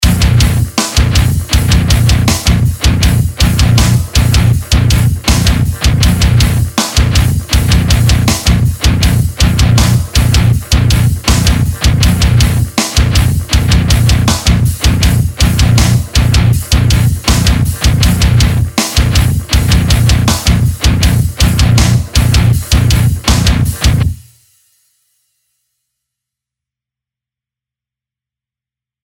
MF/POD x3/scabre pre bass/absynth/industrial modern metal